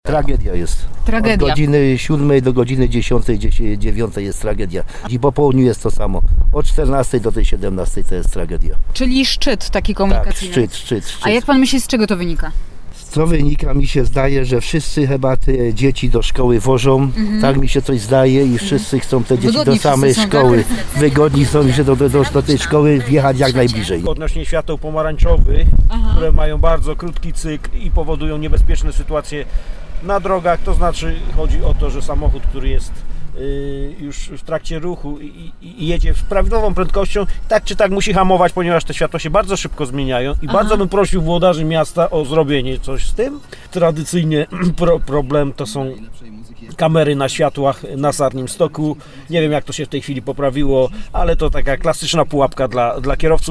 Kierowcy taksówek, z którymi rozmawialiśmy są zdania, że to wcale nie remonty dróg najbardziej przekładają się na generowanie korków.